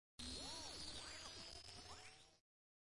毯子
描述：毯子的声音